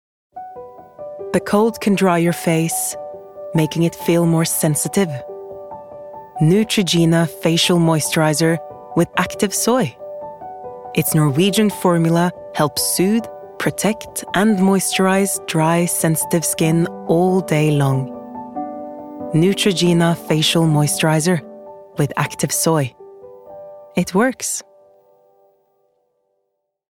• Female
Neutrogena. Scandi Accent, Smooth, With Depth